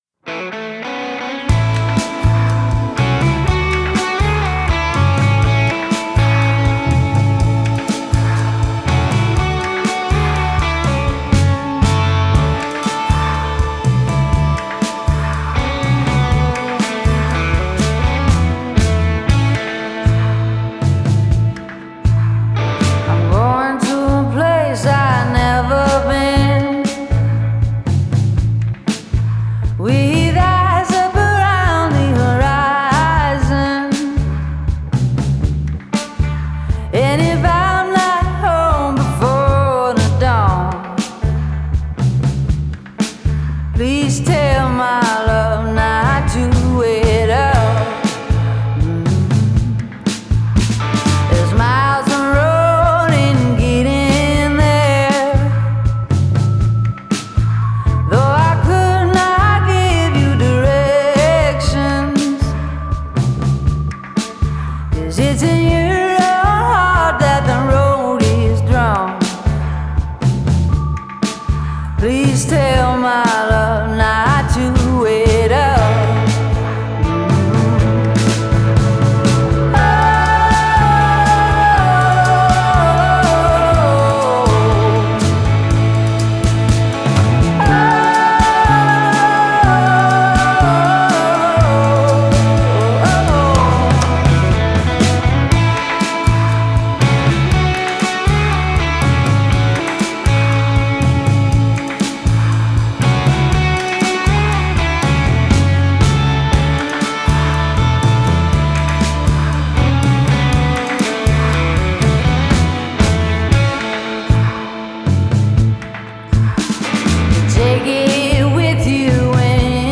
Genre: Indie Pop, Pop Rock,
Singer-Songwriter